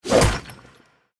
fall_box.mp3